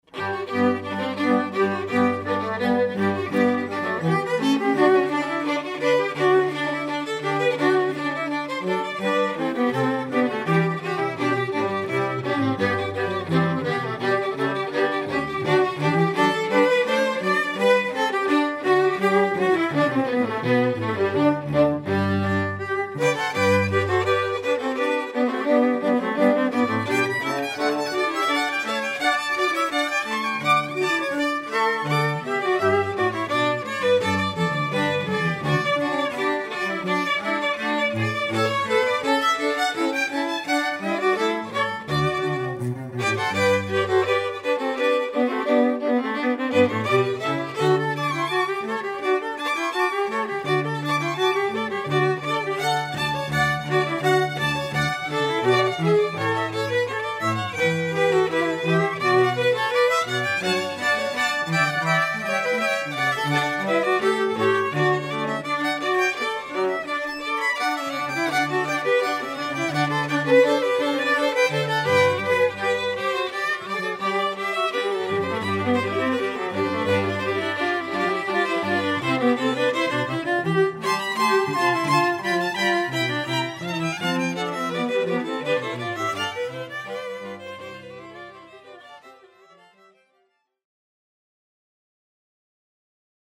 (Two Violins, Viola, & Cello)